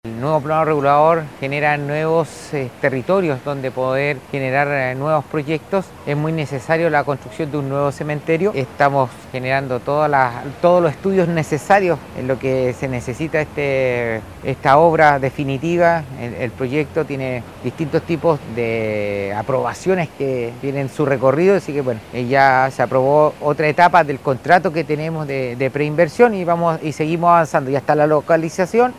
CUNA-ALCALDE.mp3